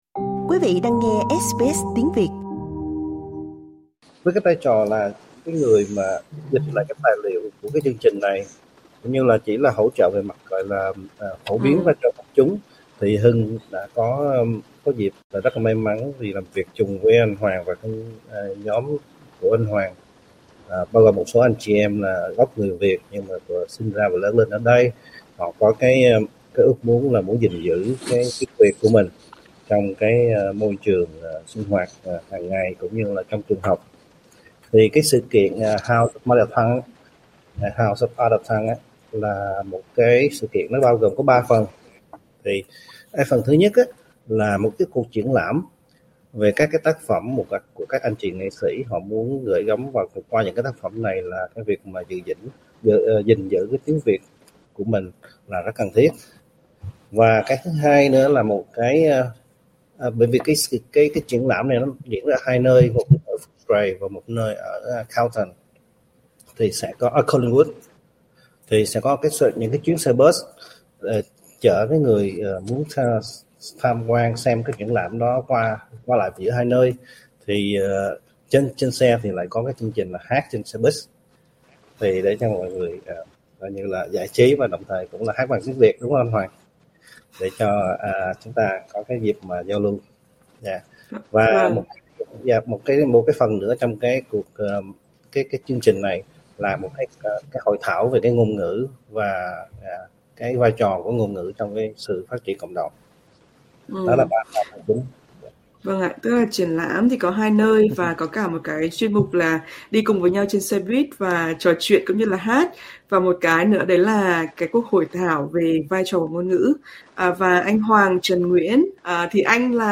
Trò chuyện với SBS Tiếng Việt